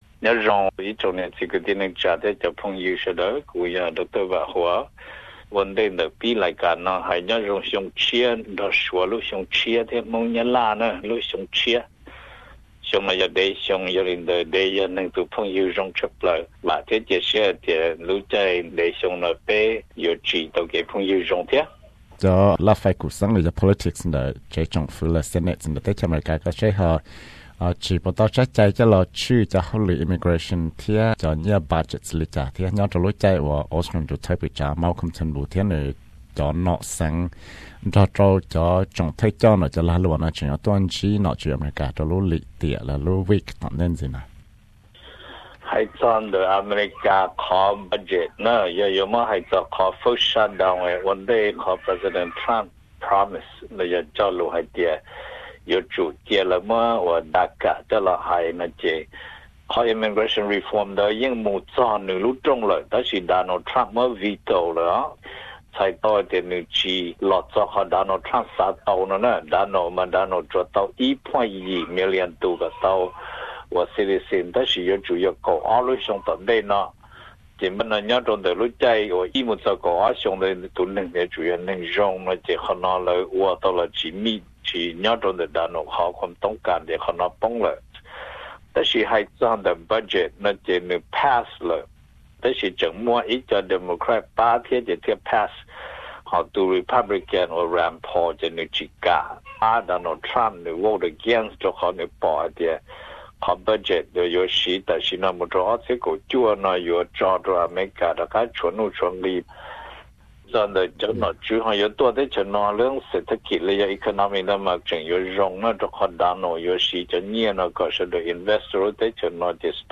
Xov xwm qhia txog nom tswv Australia mus xyuas America, US budget, kev nyab xeeb rau ntiaj teb cov me nyuam, lajfai kum xeeb ntawm cheeb tsam Africa, Middle East thiab South East Asia 18 Feb 2018